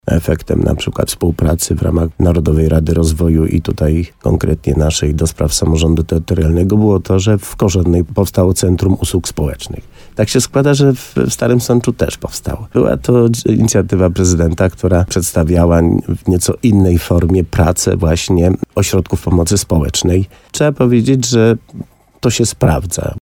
Wójt gminy Korzenna Leszek Skowron na antenie RDN Nowy Sącz skomentował swoje powołanie do Rady ds. Samorządu Terytorialnego działającej przy prezydencie Karolu Nawrockim. W programie Słowo za Słowo włodarz z Sądecczyzny podkreślił, że już wcześniej pracował w tej samej strukturze, która działała przy poprzednim prezydencie Andrzeju Dudzie.